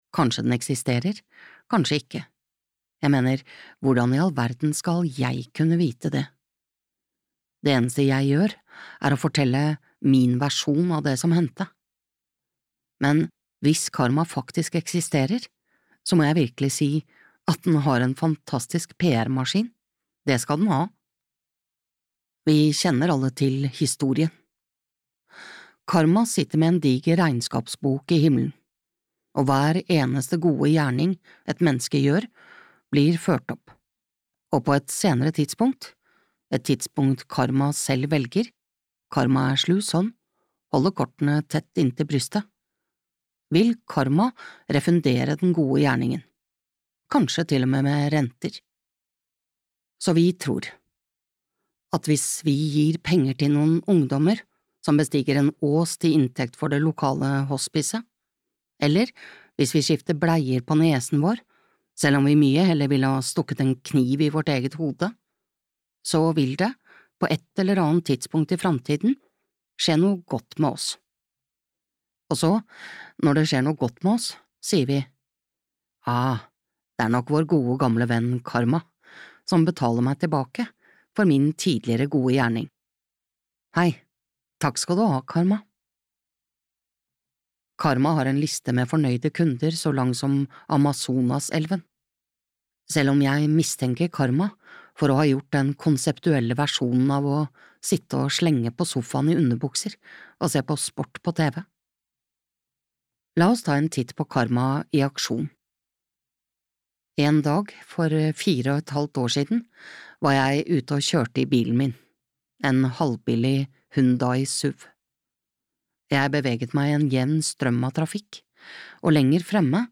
God karma (lydbok) av Marian Keyes